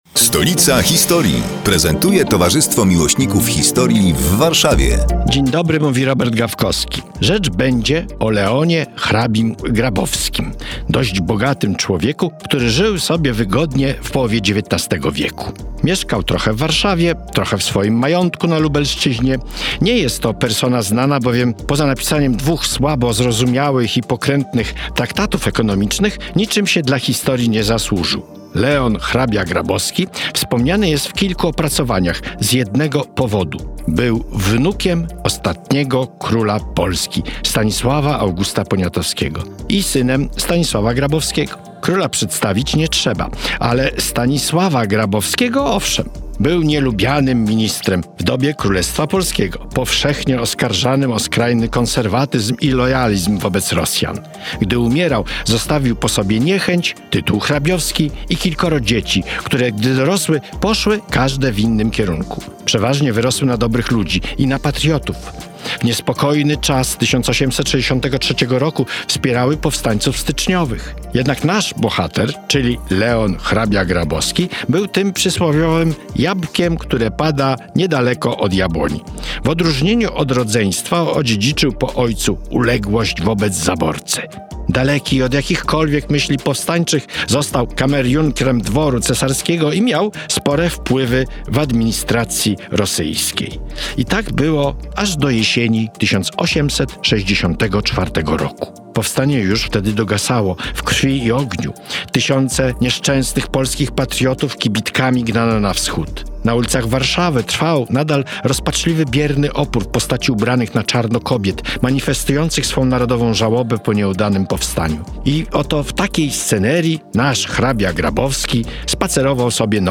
Kolejny felieton pod wspólną nazwą: Stolica historii. Przedstawiają członkowie Towarzystwa Miłośników Historii w Warszawie, które są już od dziewięciu miesięcy  emitowane w każdą sobotę o 15:15, w nieco skróconej wersji, w Radiu Kolor.